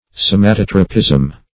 Search Result for " somatotropism" : The Collaborative International Dictionary of English v.0.48: Somatotropism \So`ma*tot"ro*pism\, n. [Gr. sw^ma, sw`matos, the body + tre`pein to turn.]